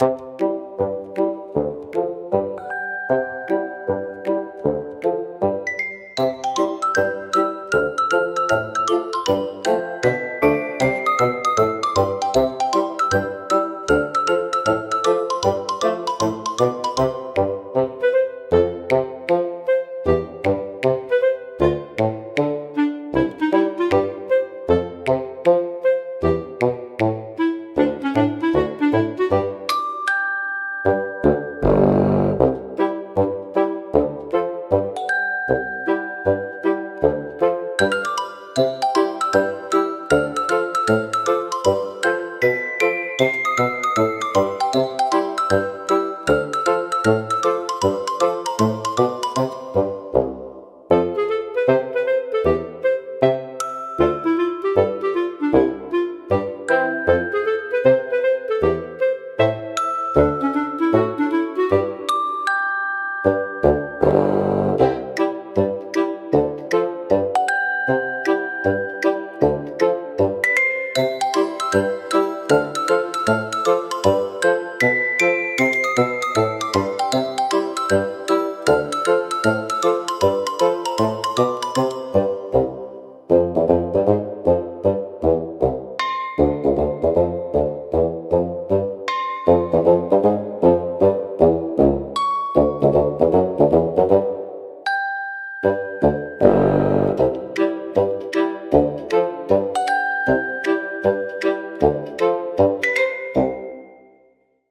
おとぼけは、バスーンとシロフォンを主体としたコミカルでドタバタした音楽ジャンルです。